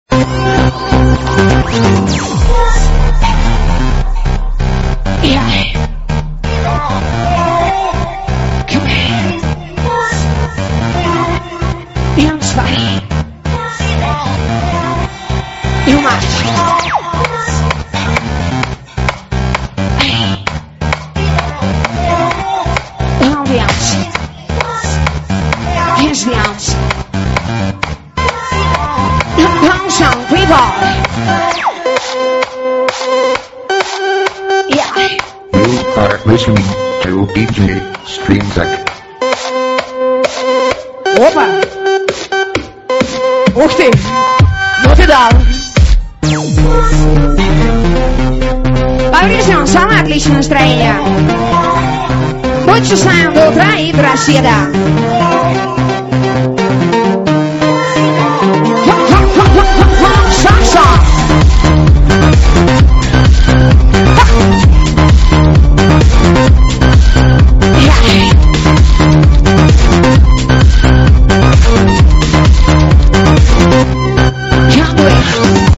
Темка эта с лайф микса